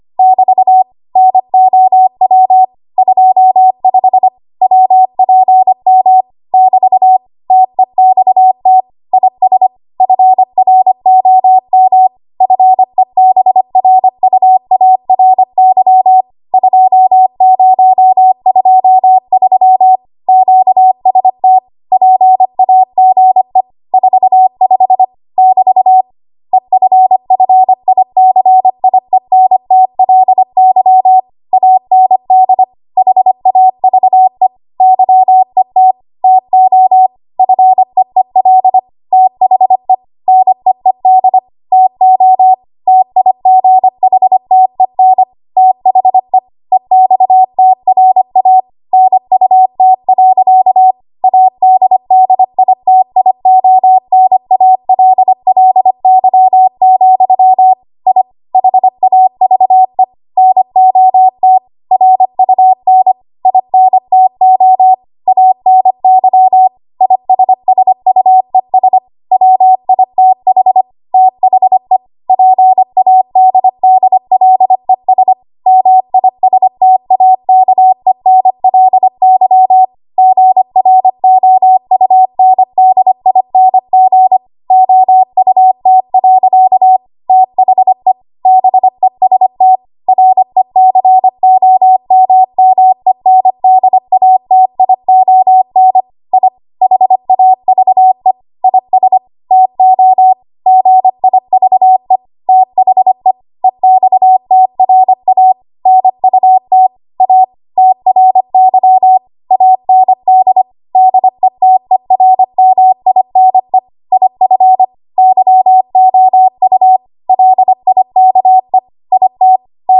25 WPM Code Practice Archive Files
Listed here are archived 25 WPM W1AW code practice transmissions for the dates and speeds indicated.
You will hear these characters as regular Morse code prosigns or abbreviations.